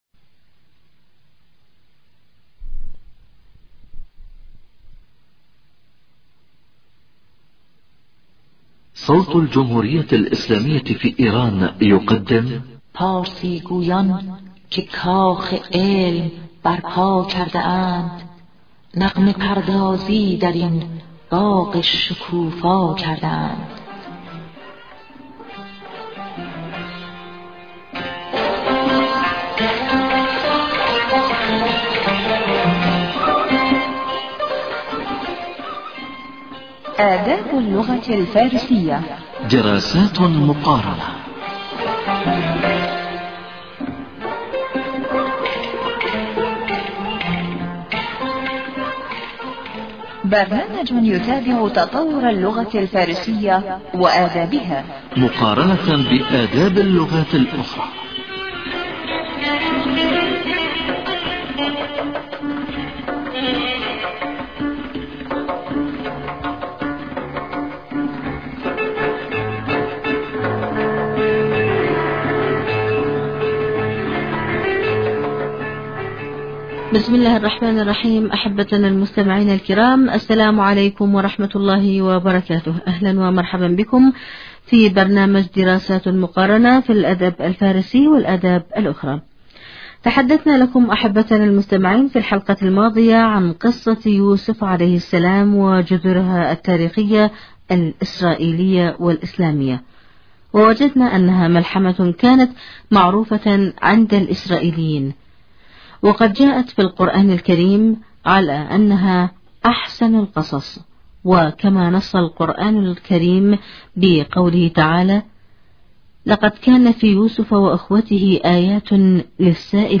ونحن في هذه الحلقة نتابع معكم الجزء الثاني من هذه القصة الملحمية العاطفية الدينية الاخلاقية، ومن اجل هذه، معنا في الاستوديو خبير البرنامج،